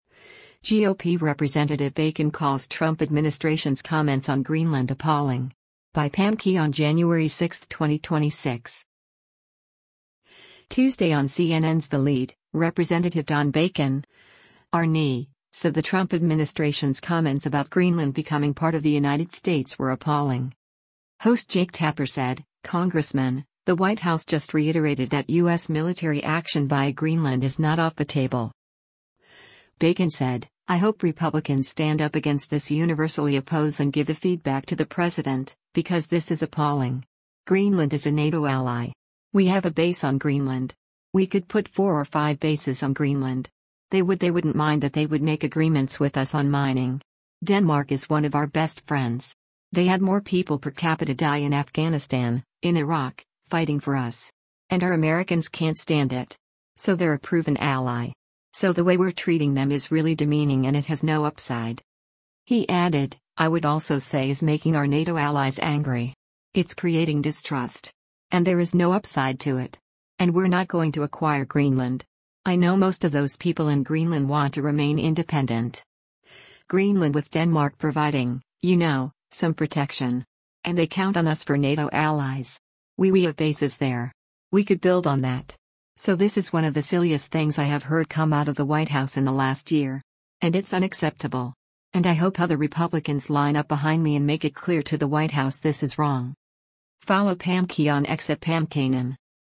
Tuesday on CNN’s “The Lead,” Rep. Don Bacon (R-NE) said the Trump administration’s comments about Greenland becoming part of the United States were “appalling.”
Host Jake Tapper said, “Congressman, the White House just reiterated that U.S. military action via Greenland is not off the table.”